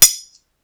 Cling1.wav